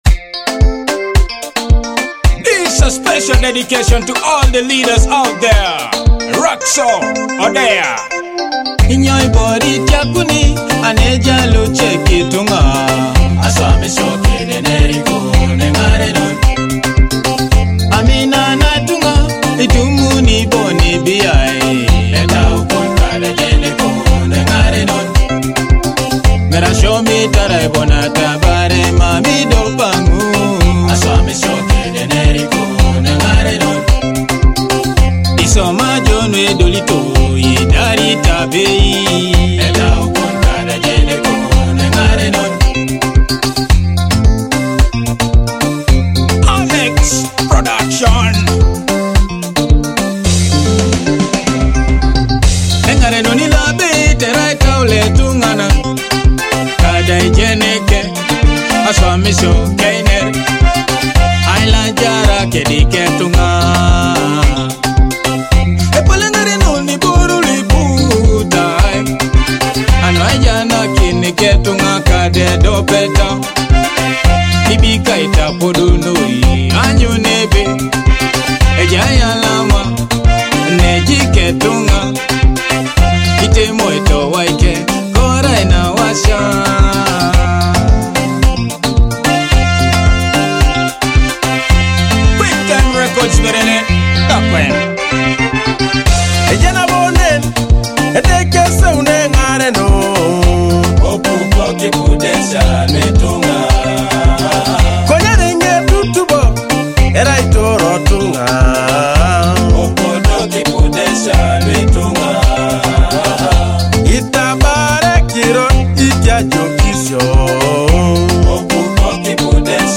captivating Teso-infused Afrobeat anthem